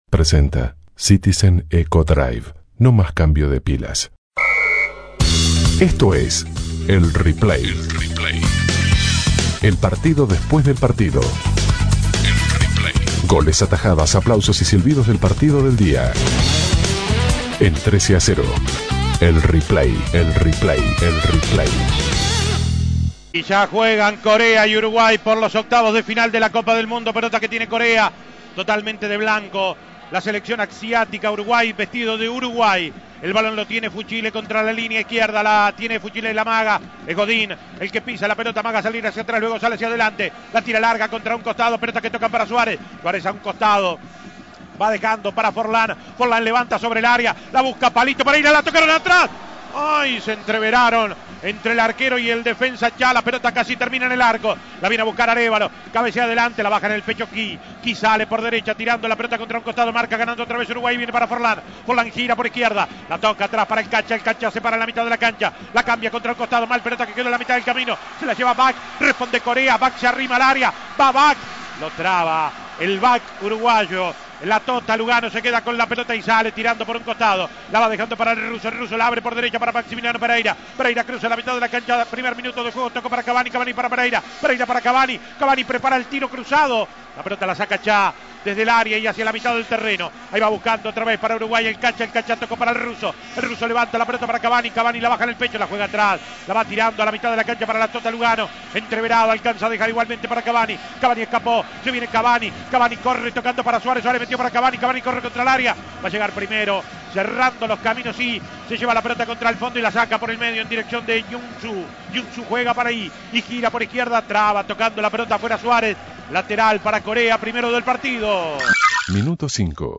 Goles y comentarios Escuche El Replay del partido Uruguay - Corea del Sur Imprimir A- A A+ Uruguay le ganó 2-1 a Corea del Sur y clasificó a cuartos de final del mundial de Sudáfrica.